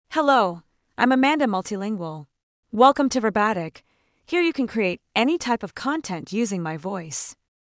Amanda MultilingualFemale English AI voice
Amanda Multilingual is a female AI voice for English (United States).
Voice sample
Listen to Amanda Multilingual's female English voice.
Amanda Multilingual delivers clear pronunciation with authentic United States English intonation, making your content sound professionally produced.